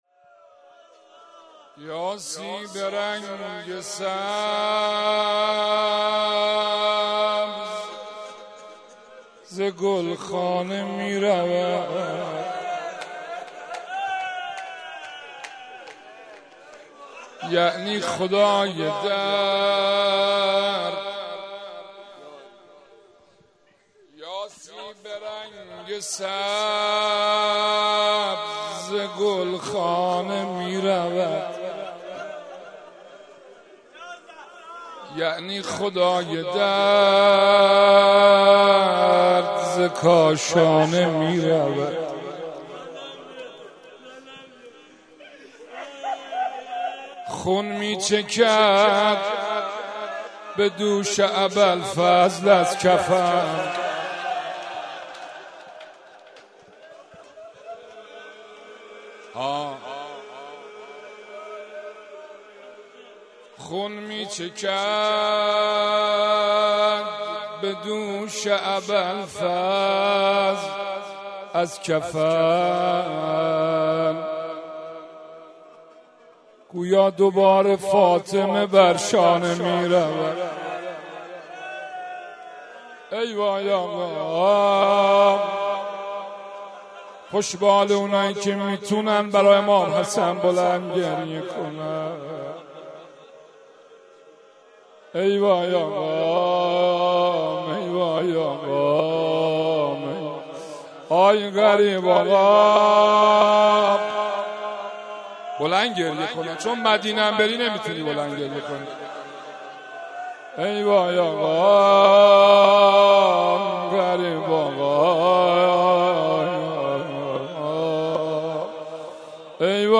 04.rozeh2.mp3